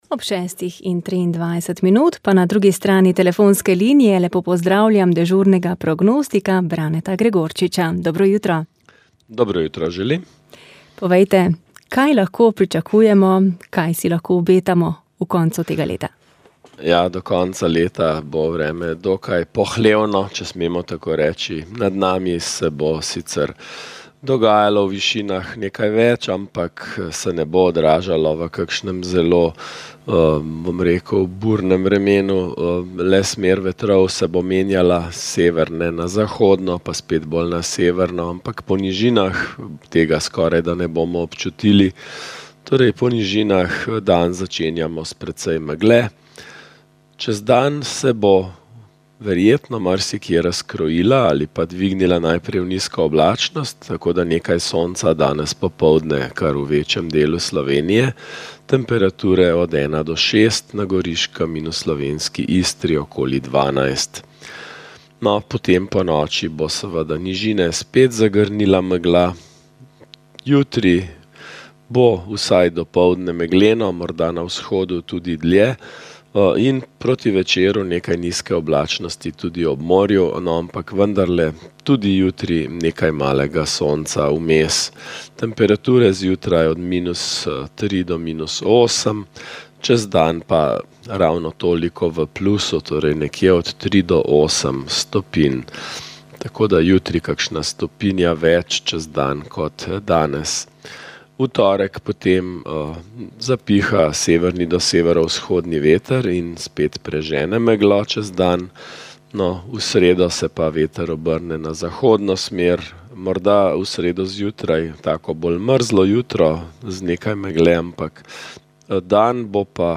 V torek 2. decembra obeležujemo Mednarodni dan boja proti suženjstvu. V tej luči smo z gosti govorili o položaju v Sloveniji, številkah s tega področja in zakonskih pomanjkljivostih ter mogočih rešitvah.